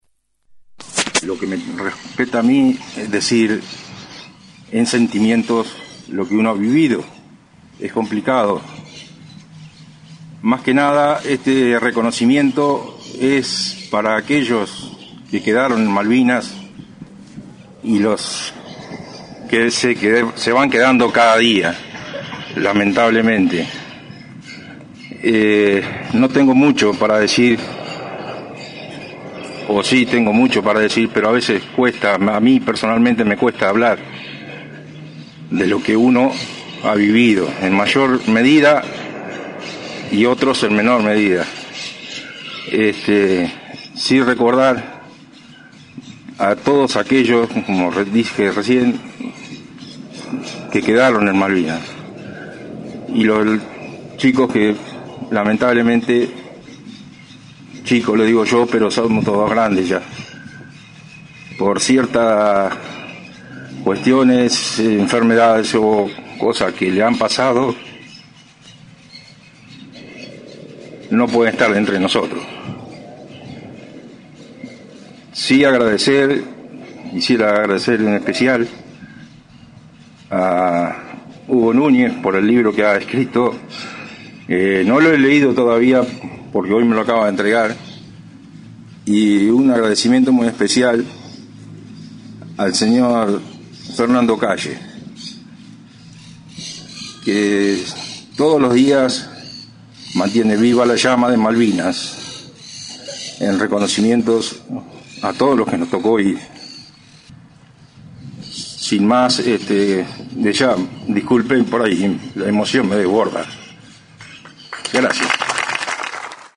Pasada las 11 de la mañana, se llevó a cabo en el nuevo monumento ubicado en Plaza Mitre el acto en conmemoración de los 41º años del desembarco en Malvinas.